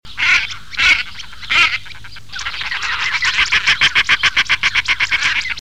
Tadorne de Belon, tadorna tadorna
tadorne.mp3